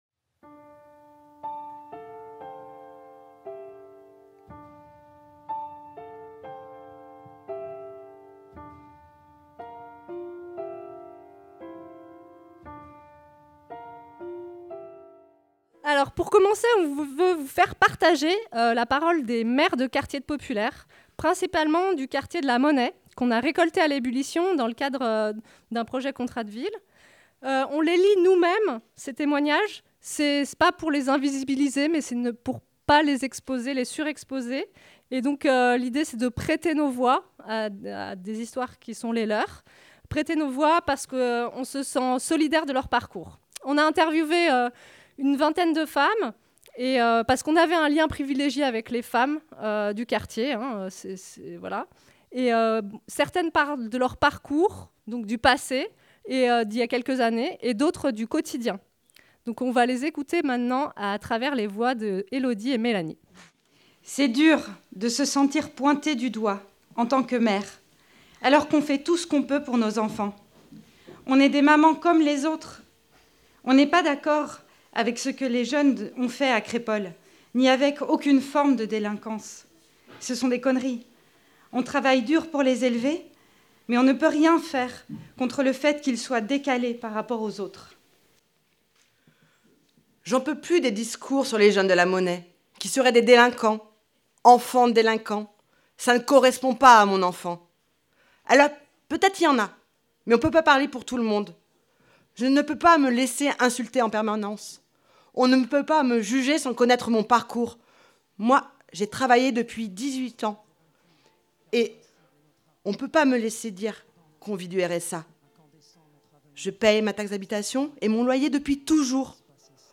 Voici une mise en voix d’extraits de ces récits :